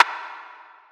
[AP] Snare ( Metro ).wav